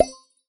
etfx_explosion_minimagic.wav